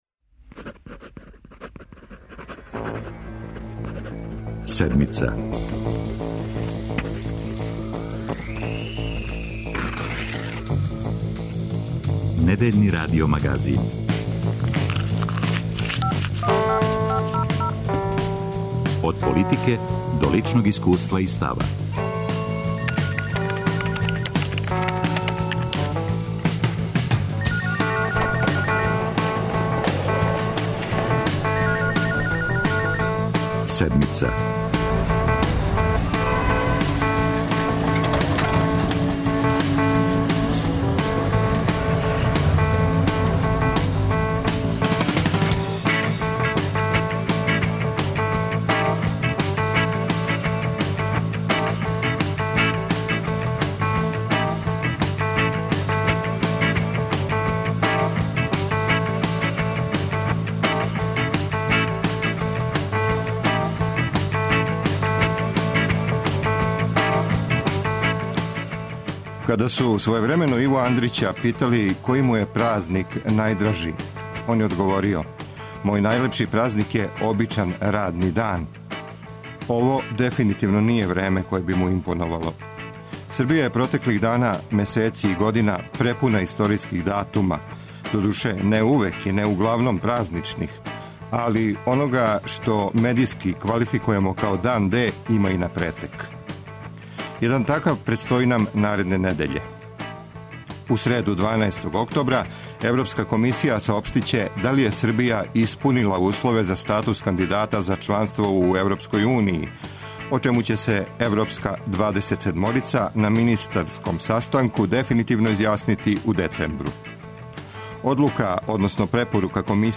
У студију Радио Београда 1 гост је потпредседник Владе, Божидар Ђелић.
Имамо ексклузивну репортажу о обнављању рада Богословије.
За наш магазин говори и бард југословенског новинарства Јуриј Густинчич.